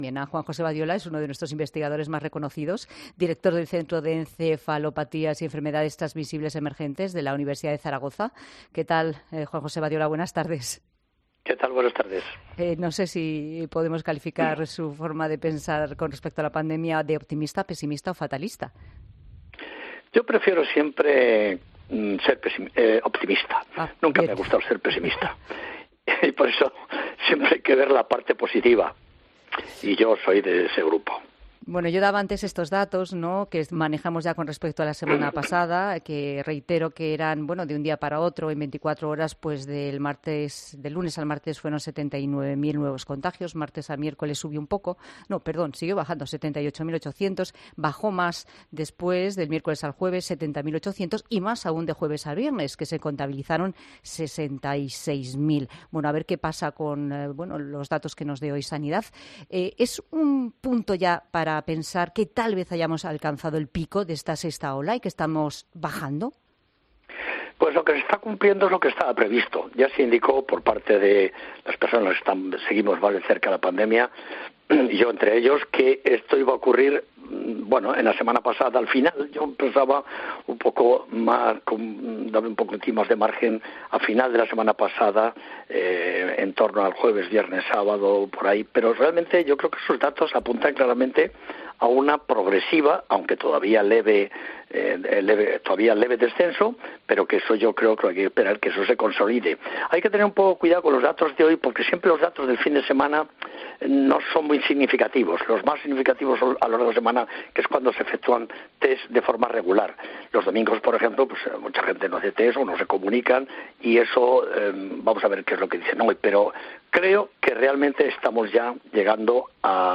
Este lunes, ha estado en 'La Tarde' de COPE para dar las últimas noticias sobre la sexta ola provocada por ómicron y su cada día más cercano final.